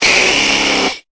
Cri de Dracaufeu dans Pokémon Épée et Bouclier.